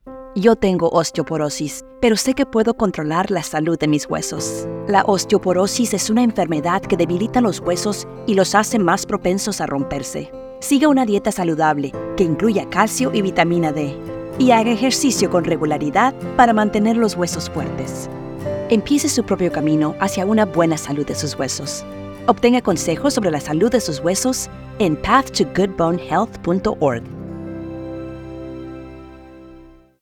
Radio PSA - Spanish